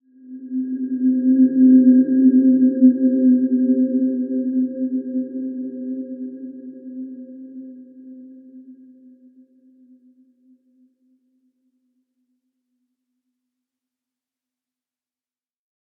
Dreamy-Fifths-C4-f.wav